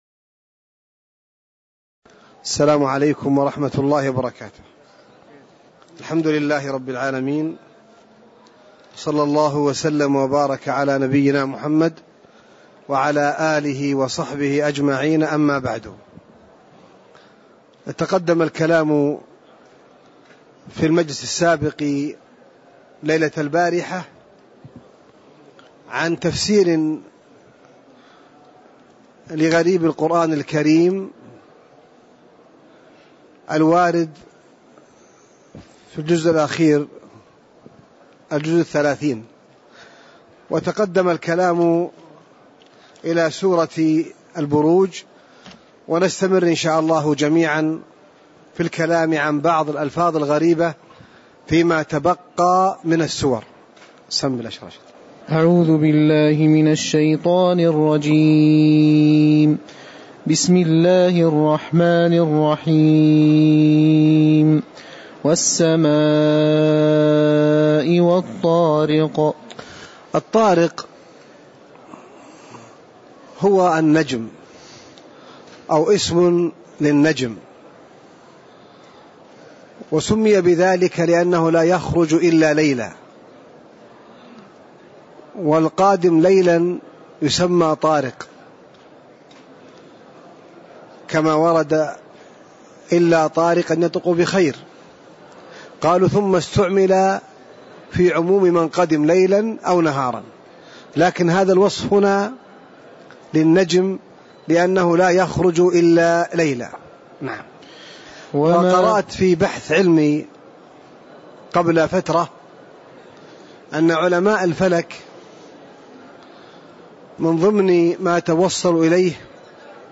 تاريخ النشر ١٣ صفر ١٤٣٨ هـ المكان: المسجد النبوي الشيخ